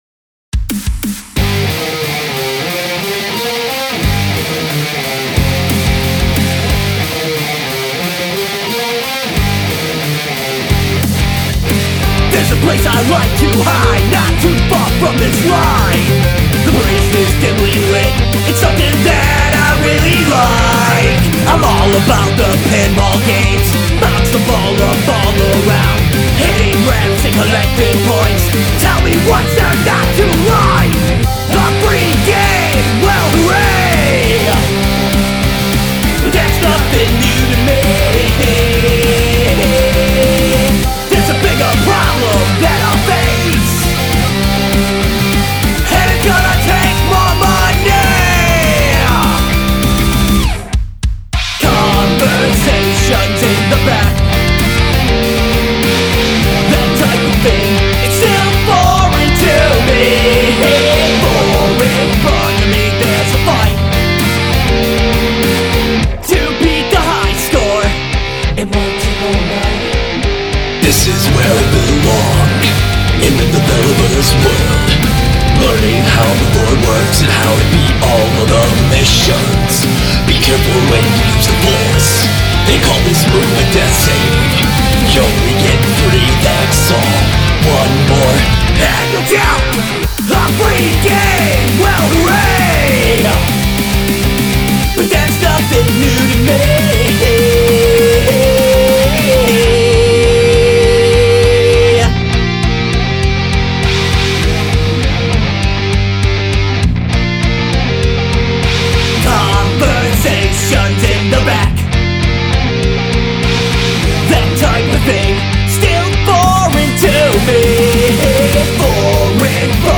electronic rock punk
high energy love guitar singing pep accordion nerdy